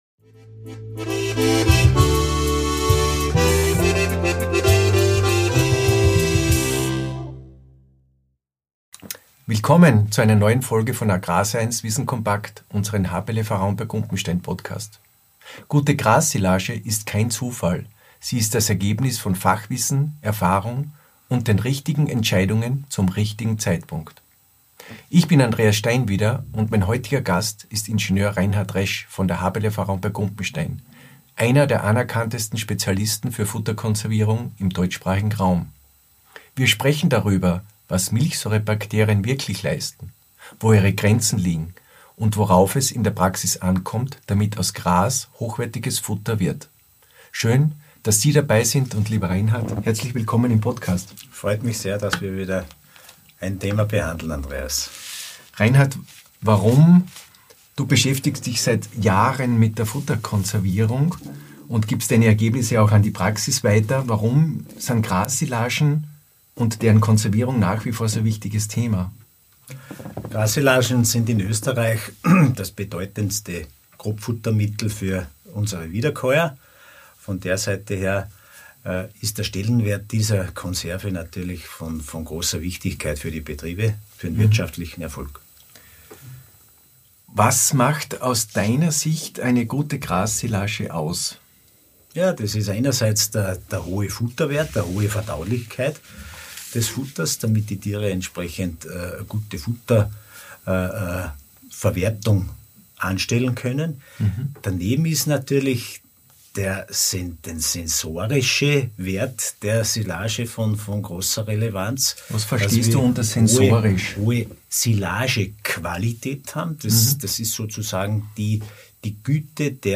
Wir klären, wann ein ergänzender Zusatz von Milchsäurebakterien über Siliermittel sinnvoll ist, wie sie den Gärverlauf beeinflussen, warum aerobe Stabilität immer wichtiger wird – und wo die Grenzen von Siliermitteln auf Basis Milchsäurebakterien liegen. Ein praxisnahes Gespräch für alle, die Futterqualität nicht dem Zufall überlassen wollen.